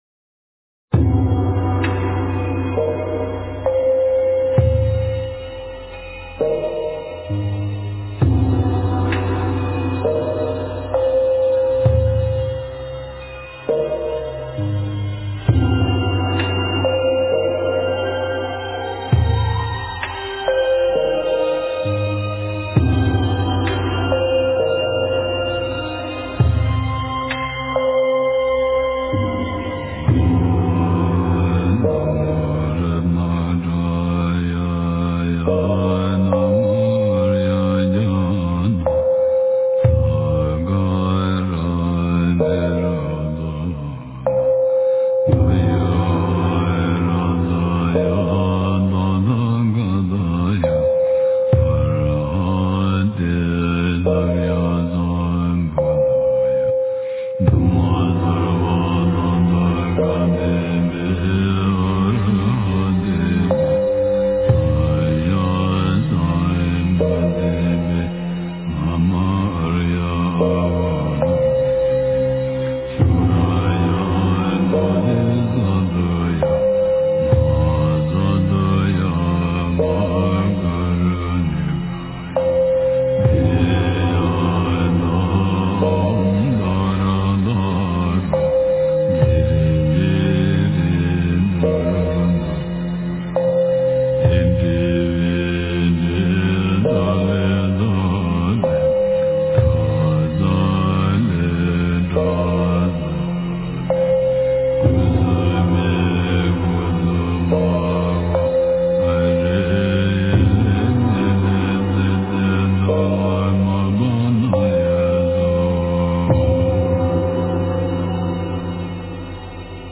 大悲咒 诵经 大悲咒--庄严播诵版 点我： 标签: 佛音 诵经 佛教音乐 返回列表 上一篇： 普贤菩萨 下一篇： 大悲咒 相关文章 大悲咒(领颂版) 大悲咒(领颂版)--大宝法王...